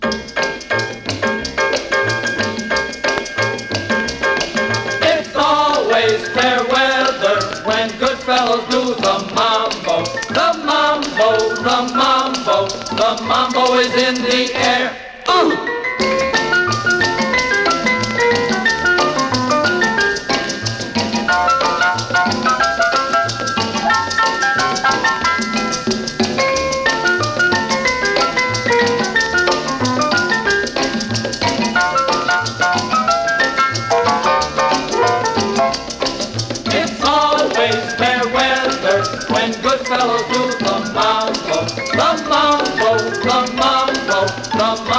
World, Latin, Jazz　USA　12inchレコード　33rpm　Mono
盤擦れキズ　プチプチノイズ